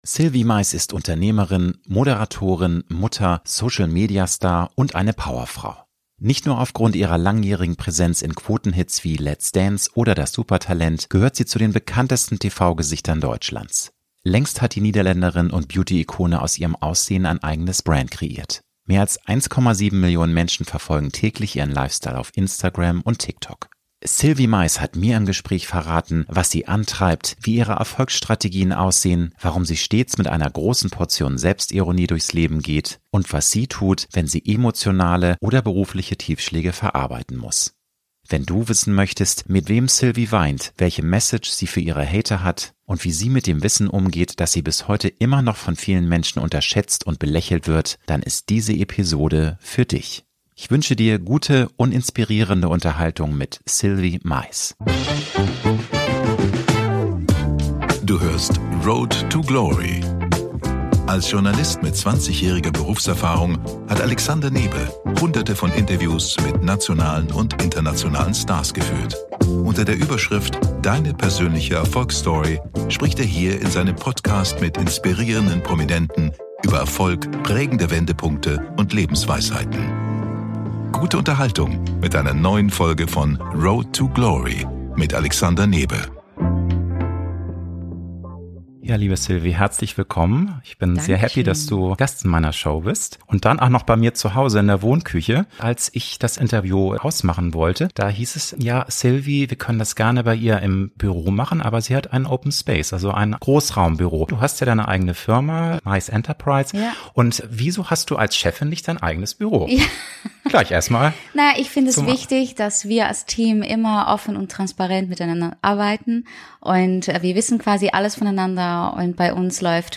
Sylvie Meis hat mir im Gespräch verraten, was sie antreibt, wie ihre Erfolgsstrategien aussehen, warum sie stets mit einer großen Portion Selbstironie durchs Leben geht und was sie tut, wenn sie emotionale oder berufliche Tiefschläge verarbeiten muss. Wenn du wissen möchtest, mit wem Sylvie weint, welche Message sie für ihre Hater hat und wie sie mit dem Wissen umgeht, dass sie bis heute immer noch von vielen Menschen unterschätzt und belächelt wird, dann ist diese Episode für dich.